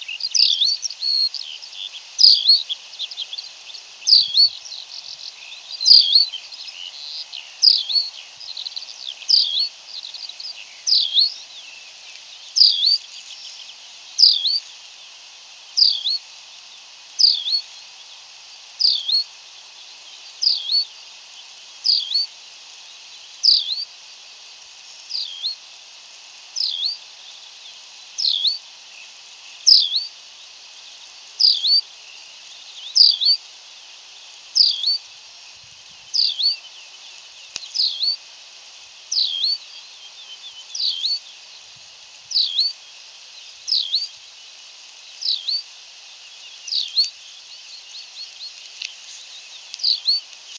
полевой конек, Anthus campestris
Administratīvā teritorijaĀdažu novads
СтатусПоёт